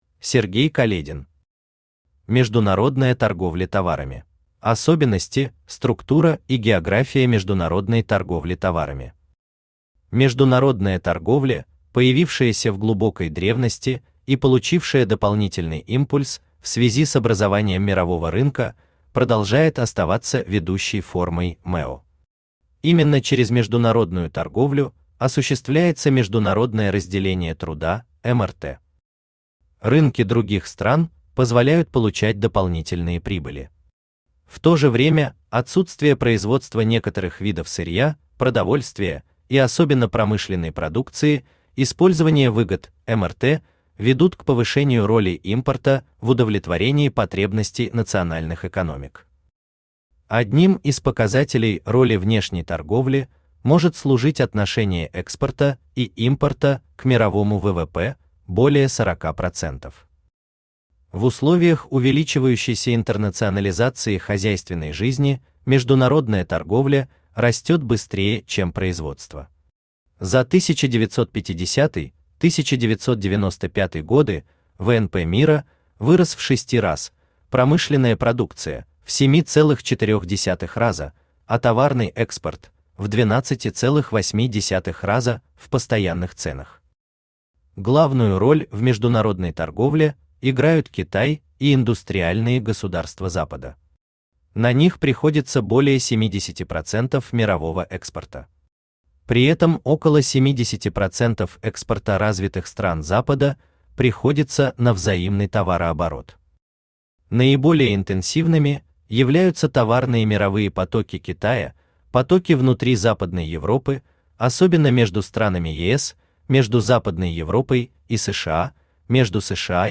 Аудиокнига Международная торговля товарами | Библиотека аудиокниг
Aудиокнига Международная торговля товарами Автор Сергей Каледин Читает аудиокнигу Авточтец ЛитРес.